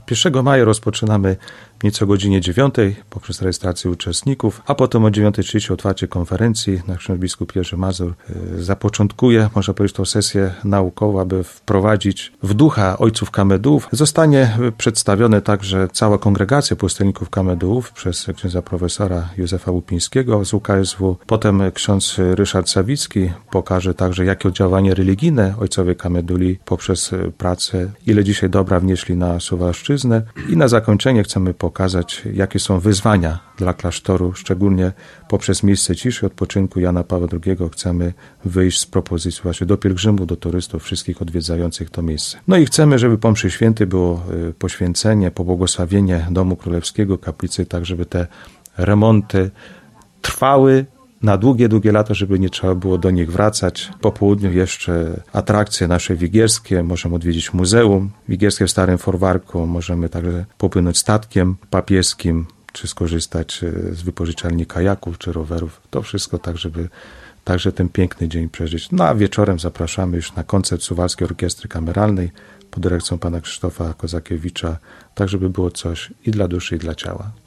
gość Radia 5